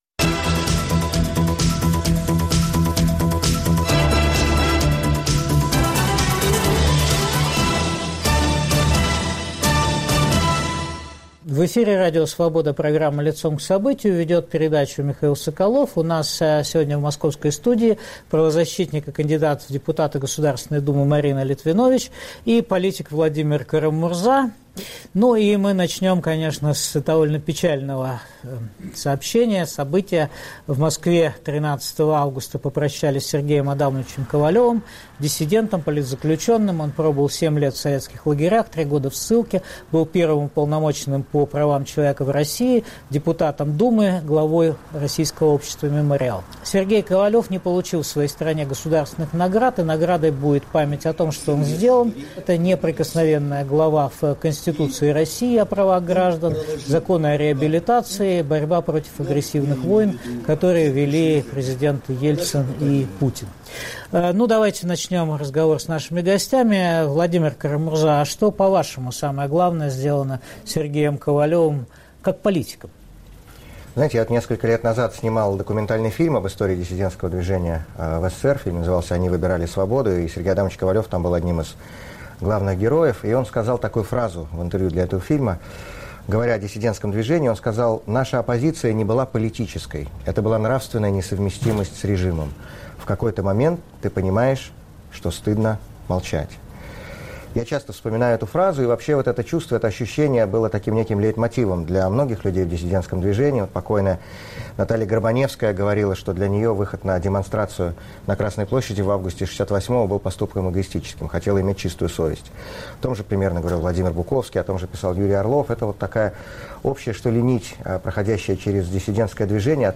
Что может один политик или правозащитник сделать сейчас? Роль личности в современной истории обсуждают правозащитник Марина Литвинович и политик Владимир Кара-Мурза.